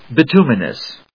/bɪt(j)úːmɪnəs(米国英語), bɪˈtu:mʌnʌs(英国英語)/